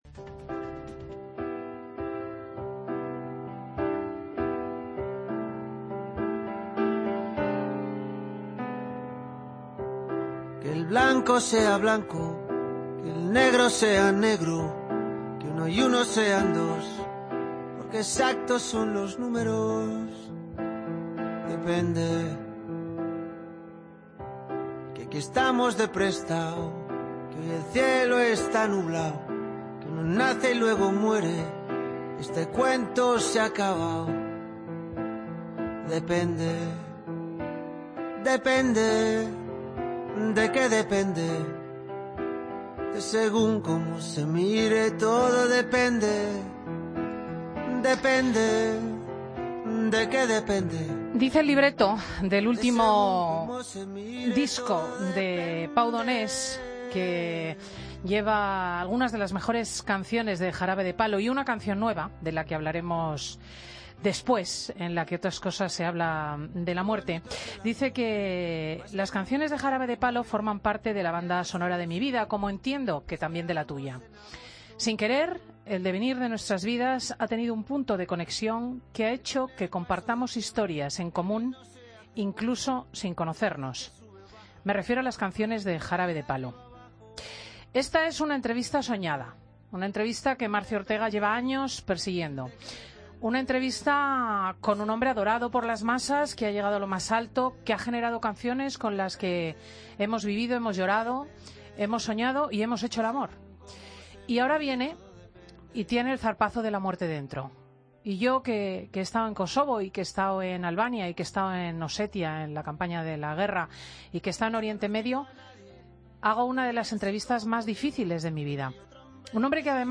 Restacatamos en Fin de Semana esta emotiva entrevista al líder de Jarabe de Palo en la que nos habló de su enfermedad y su libro